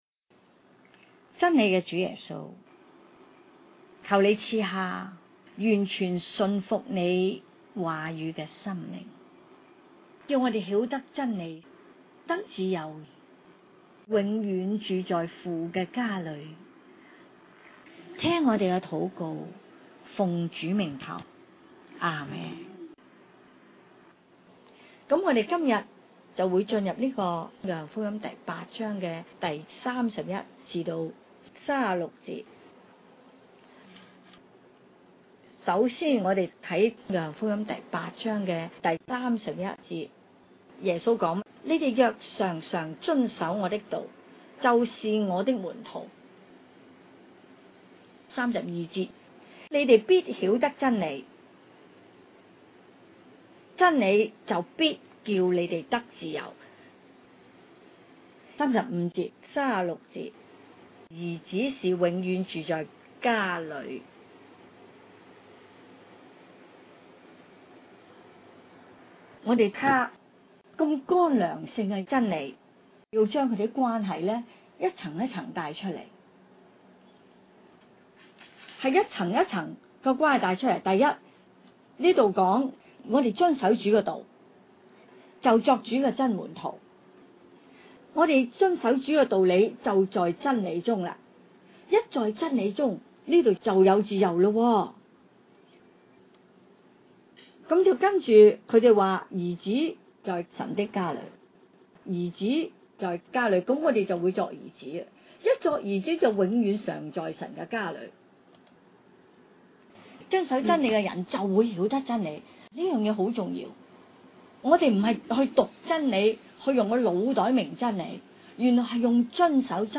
請注意： 每段教導均以認識【上帝之所是】和貫穿新舊約的重點信息－【上帝全備救贖計劃】如何安排的心意，作為明白該段經文的基礎； 若在學習中，要得到更好的果效，請在聆聽每段教導前，先【用心讀】該段經文最少兩次，然後專注在心靈裡來聆聽數次； 每段聲帶均按經文的章節次序來教導，故有些內容並不是局限於一個重點或主題來闡明； 每段教導的「聲帶」均為現場錄音，就部份或有雜音及音效未盡完善的地方，敬請包容！